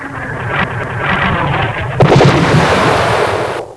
DIVCRASH.WAV